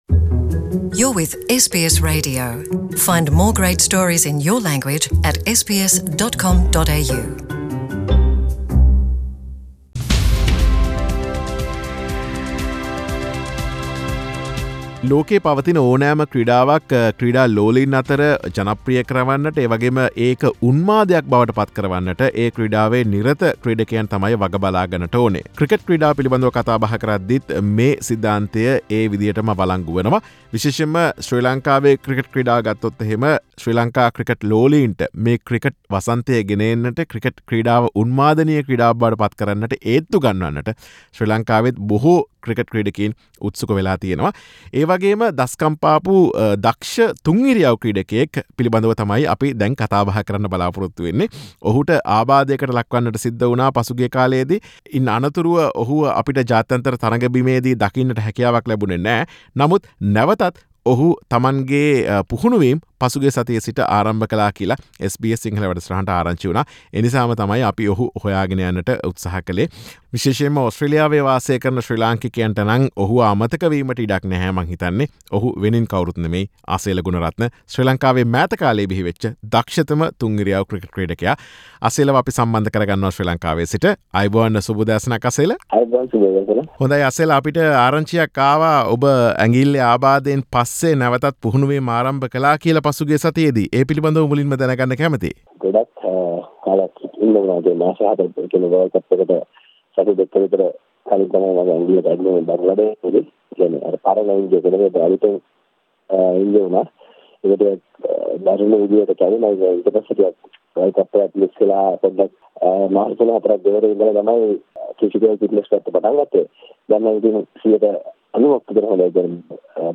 Asela Gunaratne was ruled out from International Cricket due to his injury and, he starts his practice again in last week. SBS Sinhalese conducted an exclusive interview with Asela to discuss his latest cricket life and future plans.